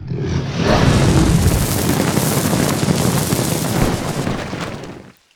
fireblow.ogg